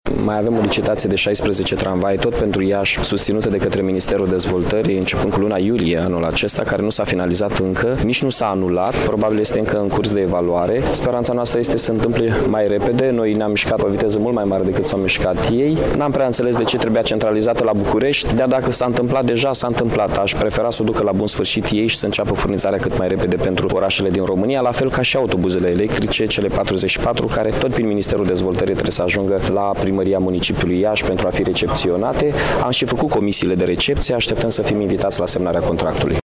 Referindu-se la cea de-a doua etapă de licitaţie, respectiv achiziţionarea a încă 16 tramvaie, primarul Iaşului, Mihai Chirica, a precizat că aceasta se ţine la Bucureşti şi deocamdată nu s-a finalizat: